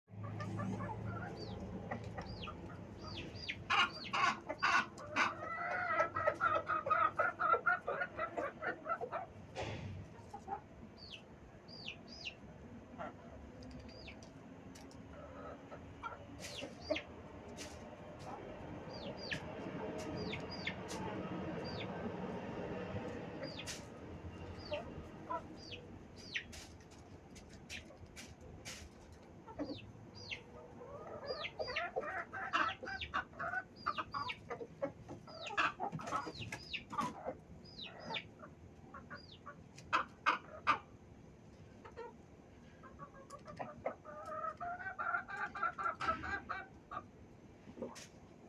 Sound Effects
Chicken Coop Noise